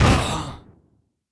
airship_die2.wav